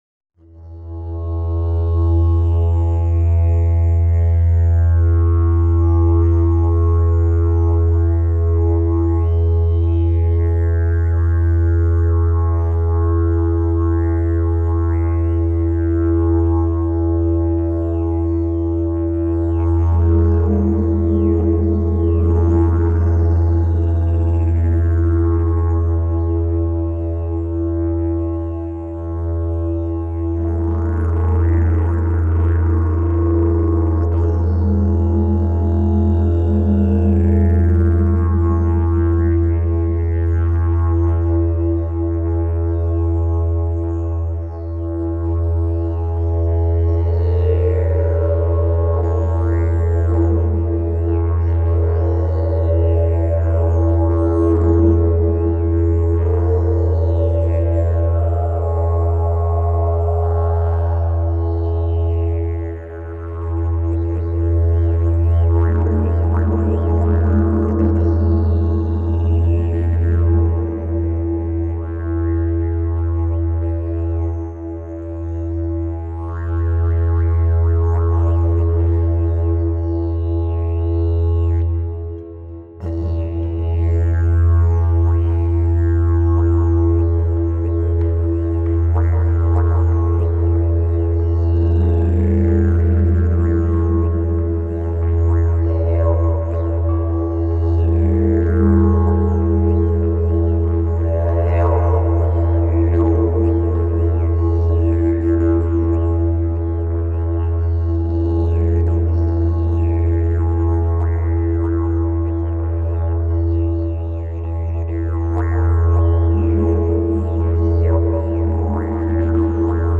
quartz crystal didjeridu
crystal-didjeridu.mp3.mp3